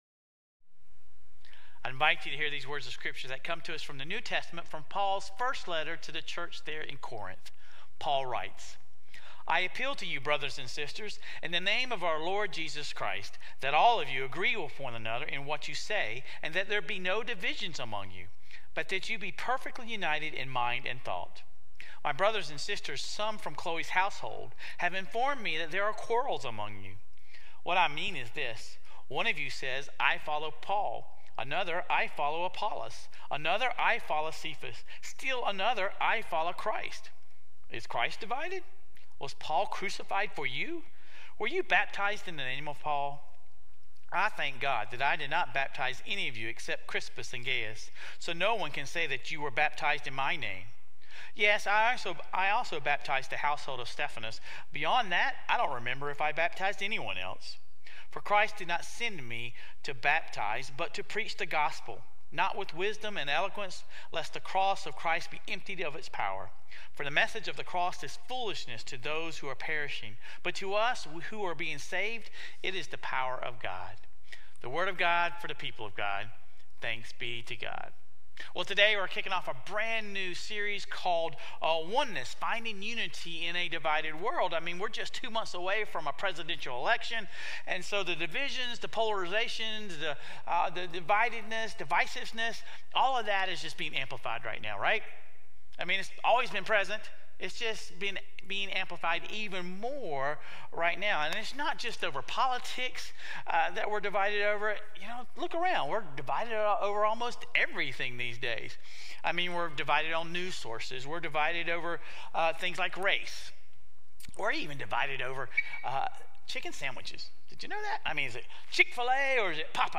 What does it mean and what does it look like for Christians to find unity in the midst of a divided world? This week, we begin our exploration of unity in the church, using Paul's first letter to the Corinthians as a guide. Sermon Reflections: What is the primary purpose or unity within the Christian community?